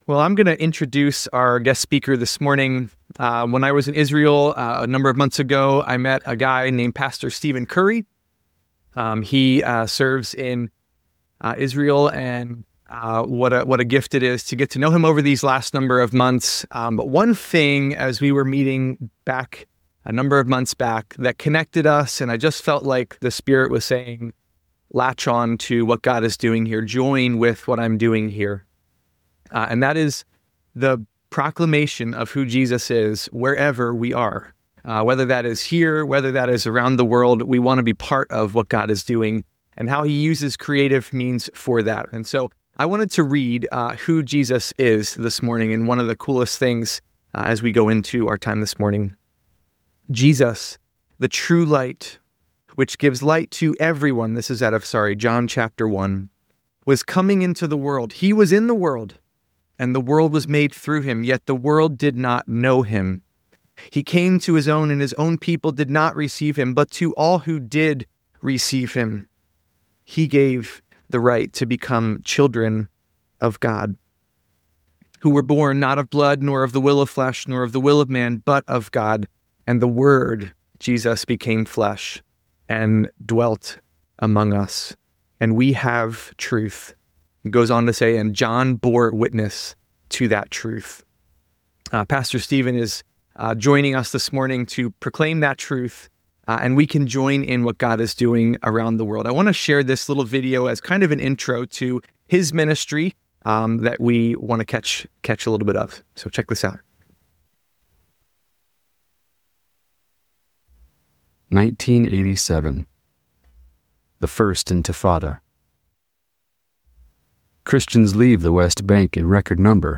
The sermon
The time concludes with a prayer and a call for community support.